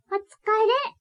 ボイス
キュート女性挨拶